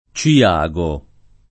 Ciago [ © i- #g o ]